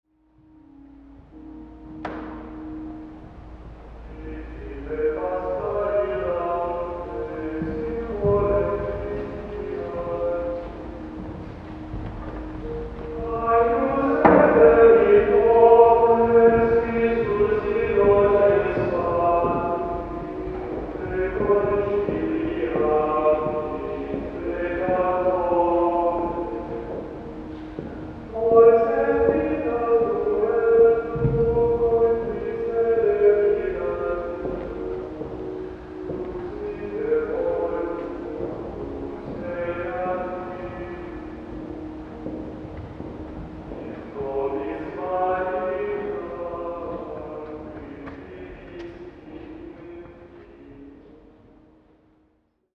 Alcune persone ammiravano soffita e decorazioni. Una signora pregava. Mi sono seduto.
All’interno della collegiata dei Santi Quirico e Giulitta si sentiva questo:
collegiata-di-San-Quirico.mp3